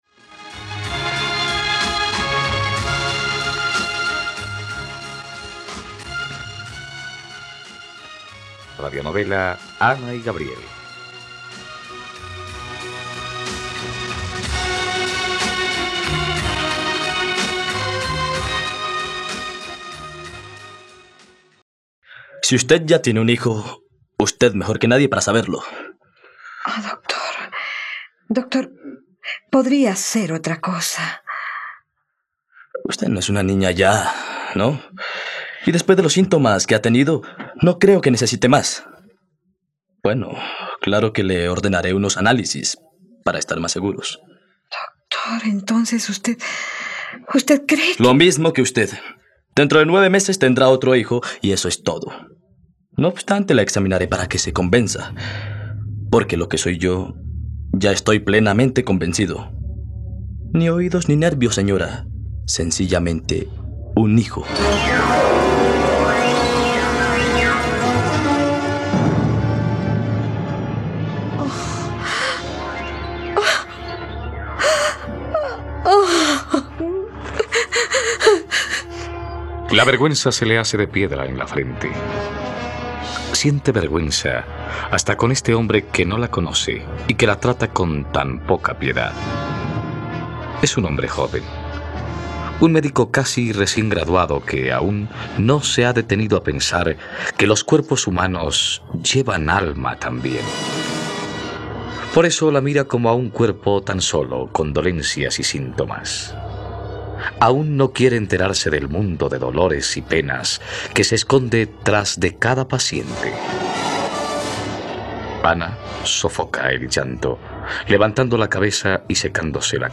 ..Radionovela. Escucha ahora el capítulo 103 de la historia de amor de Ana y Gabriel en la plataforma de streaming de los colombianos: RTVCPlay.